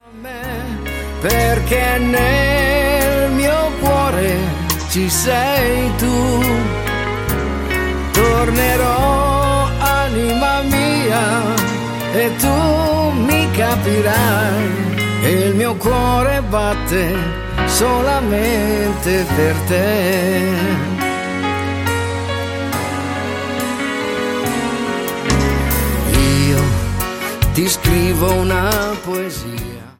SLOW  (03,36)